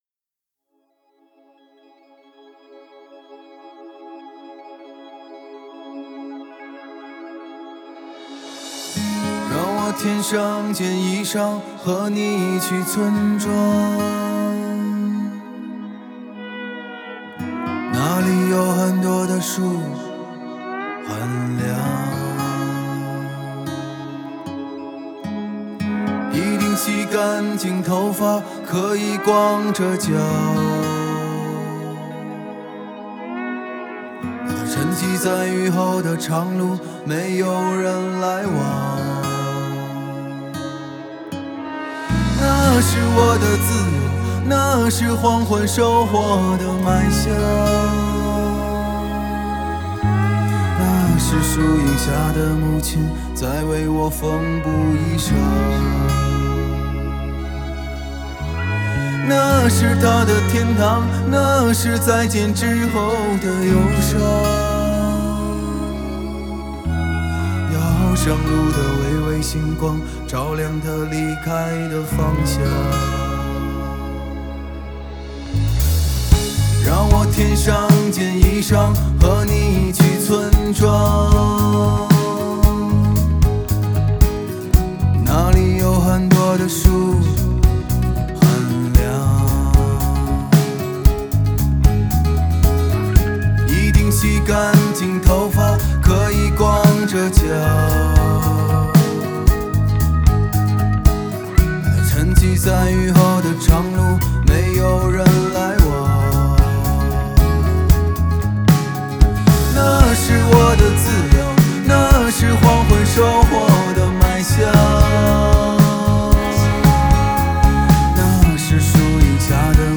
如果你仔细听，会发现唱片中有很多特别的音效：家里的旧电视、微信的语音条，甚至有电磁炉的提示声等等。